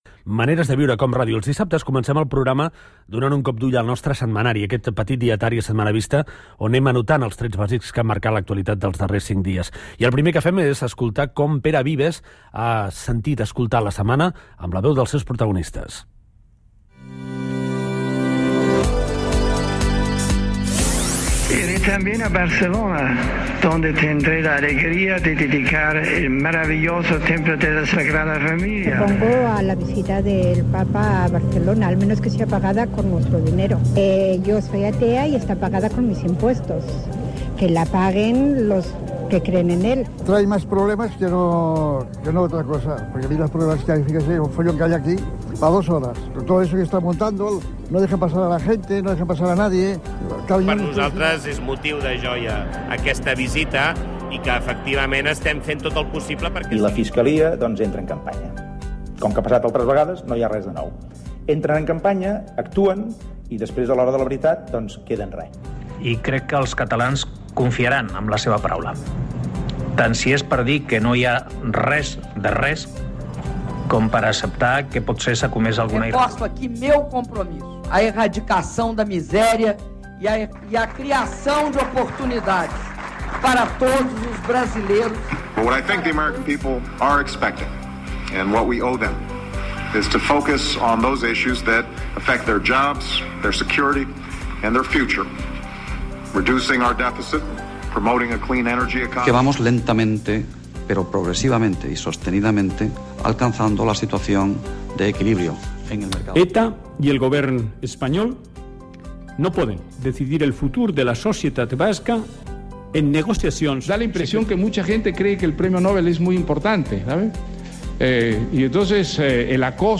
Secció de resum informatiu setmanal amb les veus dels protagonistes. Indicatiu del programa, hora, "El setmanari", a 21 dies de les eleccions al Parlament de Catalunya, el cas Millet, eleccions al Congrés dels EE.UU.
Info-entreteniment